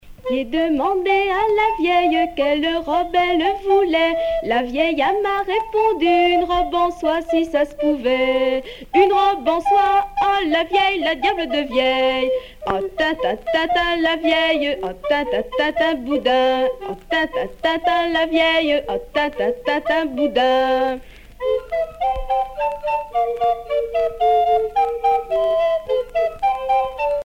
danse : ronde
Genre énumérative
Musique traditionnelle paysanne du Haut-Poitou